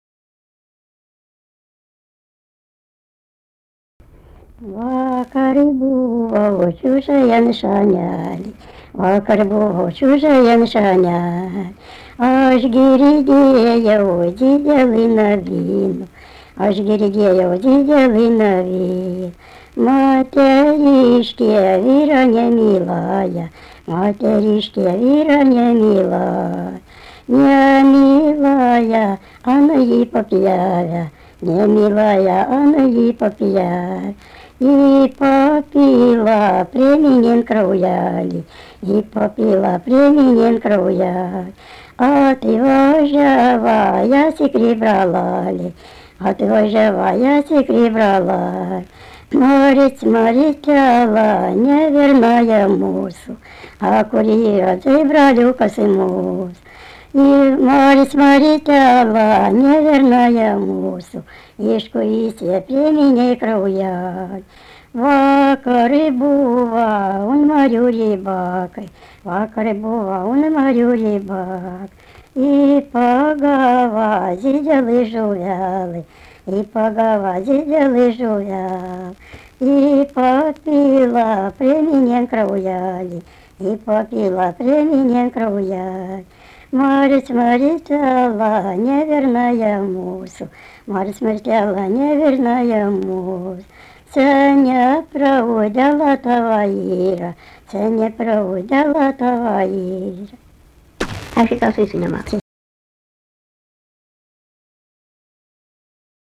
sutartinė
Erdvinė aprėptis Gilūtos, Gilūtų k.
Atlikimo pubūdis vokalinis